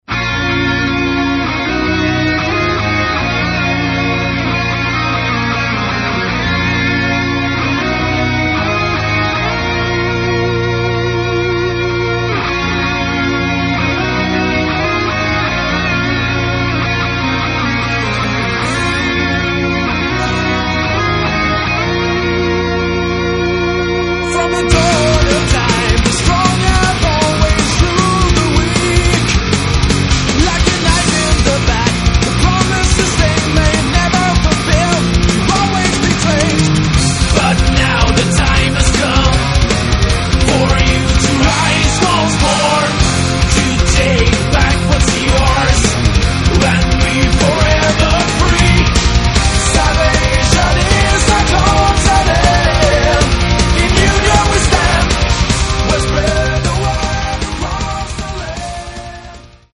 Третий альбом шведского melodic power metal коллектива.